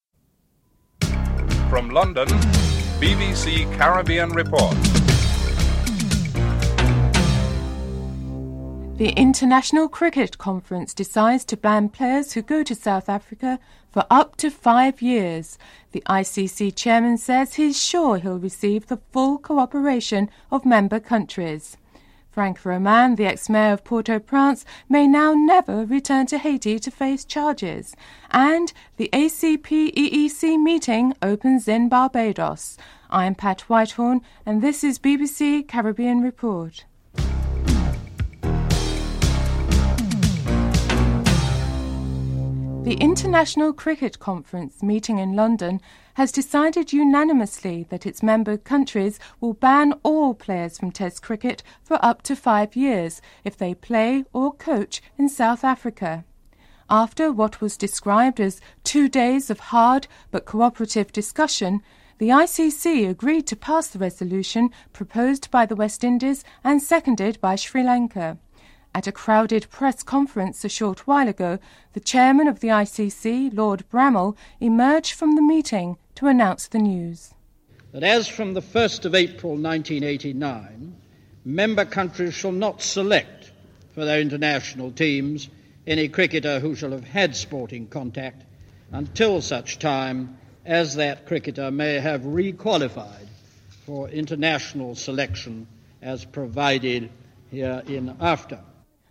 1. Headlines (00:00-00:41)
3. Interview with the Foreign Minister of the Dominican Republic on any request for the extradition of Franck Romain for his alleged involvement in a a massacre in Haiti (03:37-05:54)
6. Erskvine Sandiford, Prime Minister of Barbados opens the ACP/EEC Meeting in Barbados (09:06-11:46)